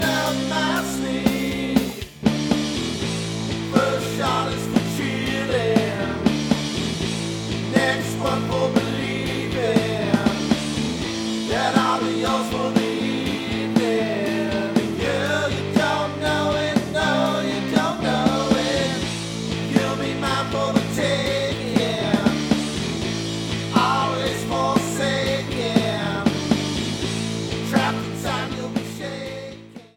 Keeping with a classic rock vibe
Vocals, Guitar, Bass, Drum programming
Lead Guitar